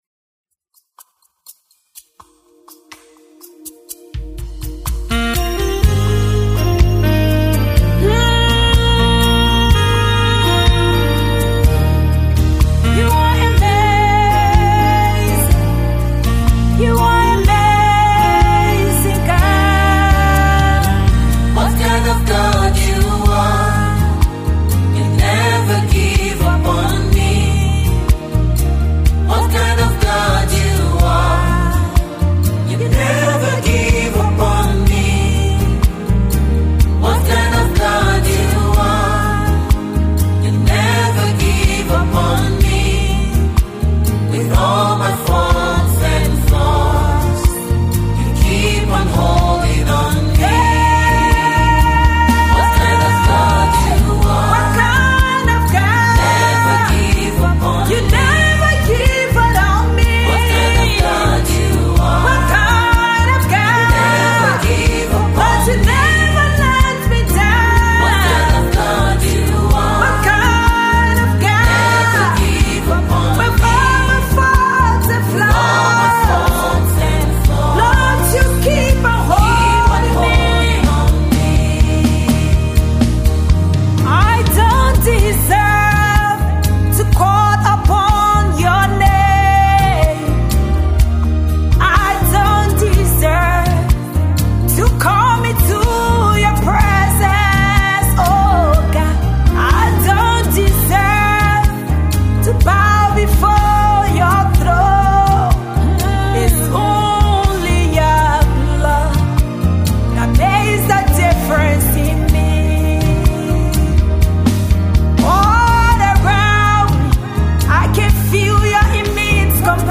Thanksgiving song